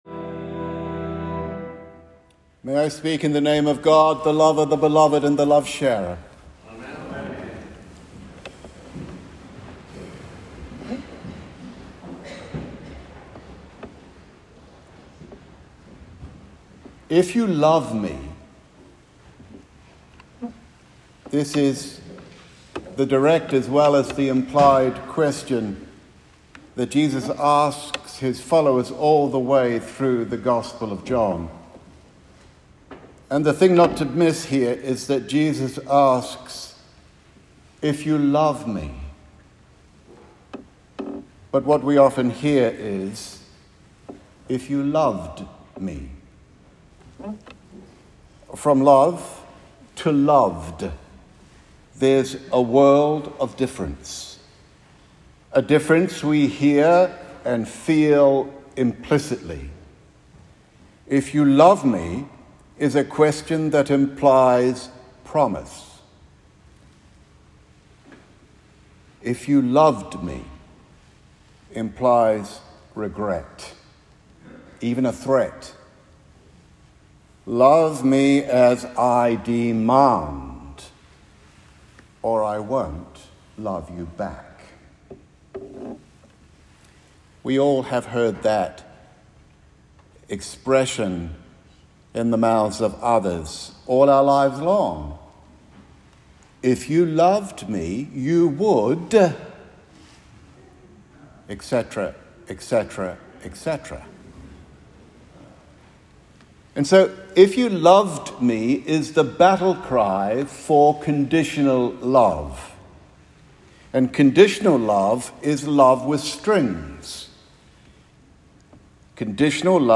The Sixth Sunday of Easter
Recording of the sermon